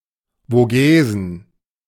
The Vosges (/vʒ/ VOHZH,[1][2][3] French: [voʒ] ; German: Vogesen [voˈɡeːzn̩]